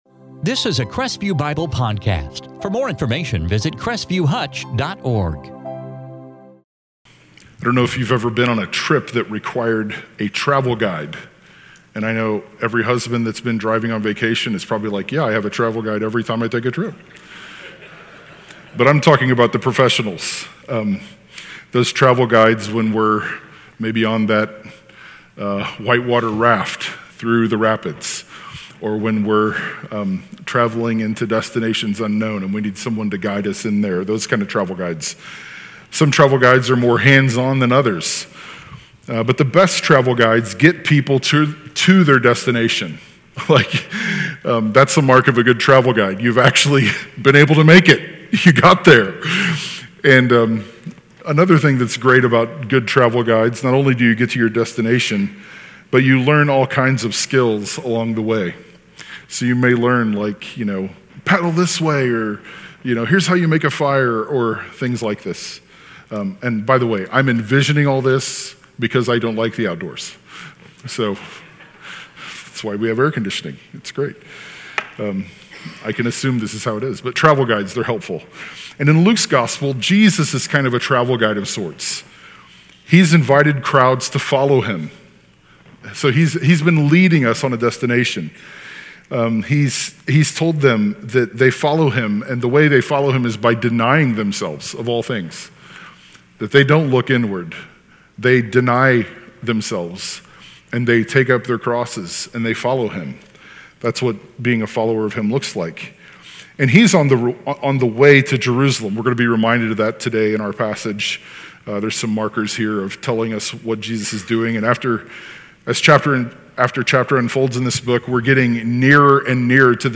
2024 Gospel of Luke Luke 17:1-19 In this sermon from Luke 17:1-19